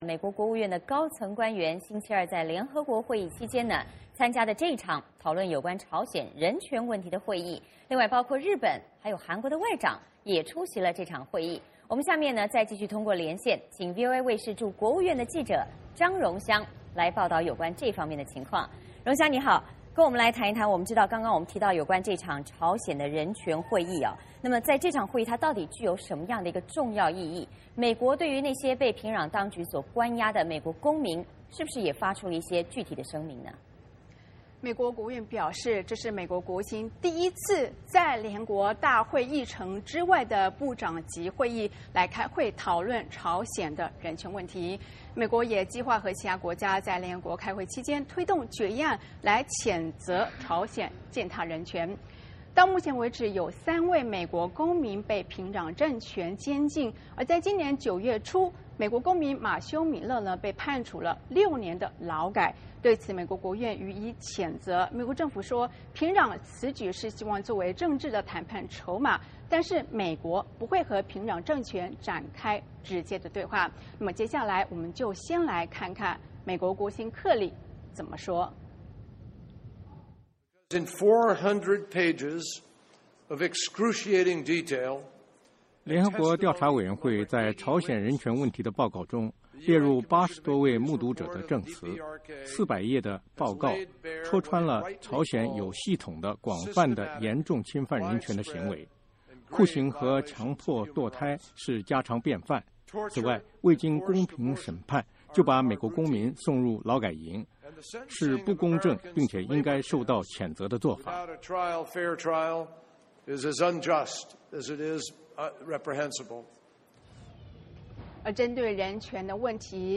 VOA连线：谴责朝鲜人权 联合国计划推动决议案